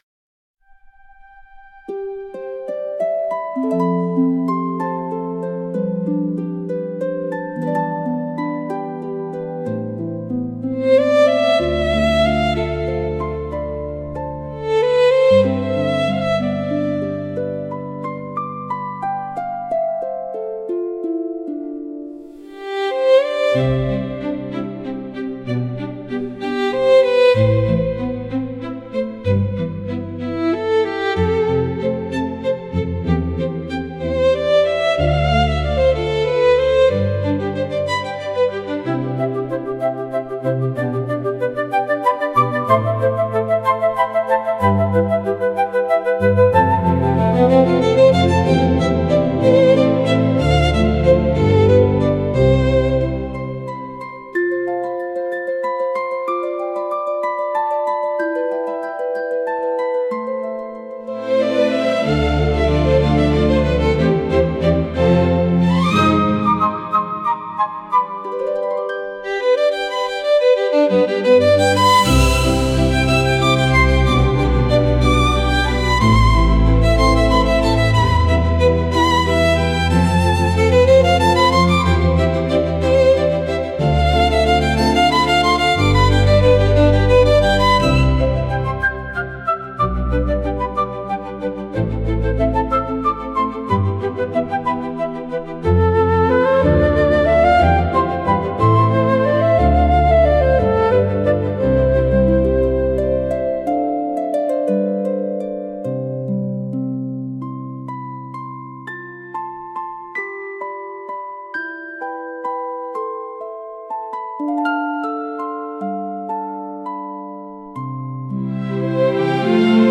And then of course I generated another piece with violins and strings